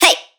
VR_vox_hit_hey.wav